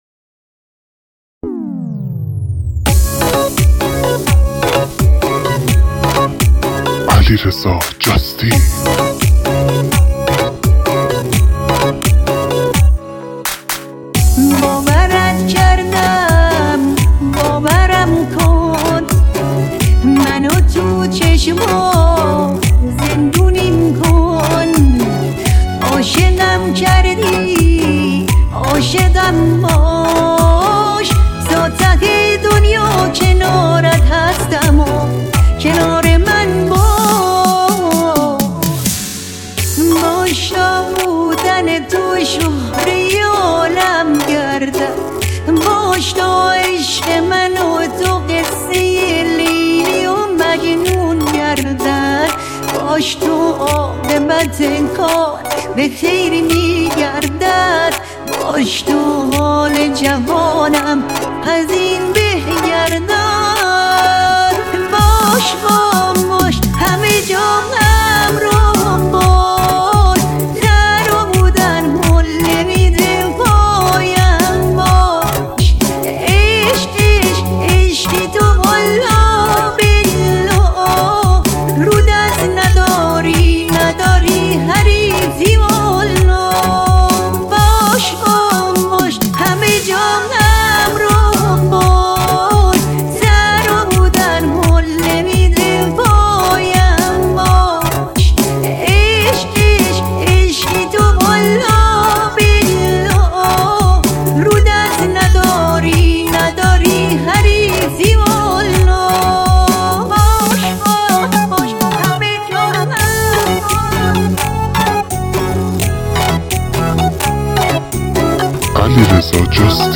نوستالژی